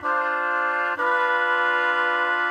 Index of /musicradar/gangster-sting-samples/95bpm Loops
GS_MuteHorn_95-D2.wav